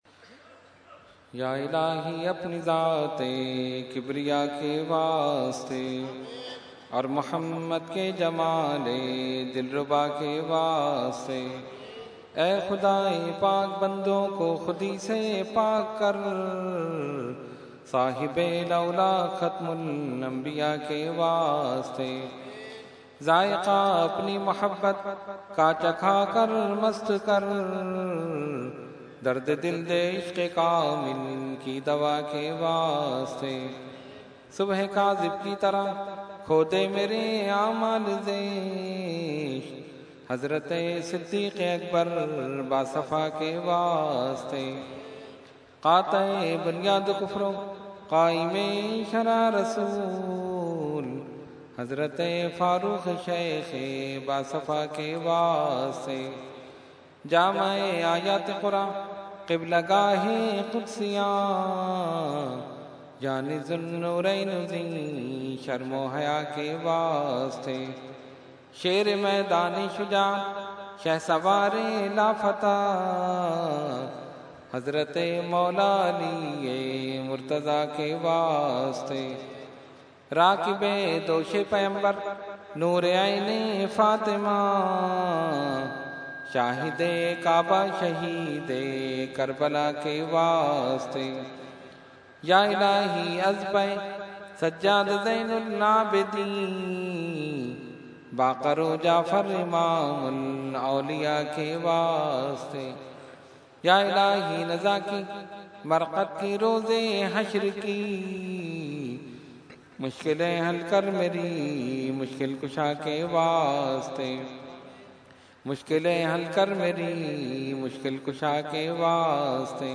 Dua – Urs e Makhdoom e Samnani 2013 Day3 – Dargah Alia Ashrafia Karachi Pakistan